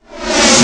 VEC3 Reverse FX
VEC3 FX Reverse 14.wav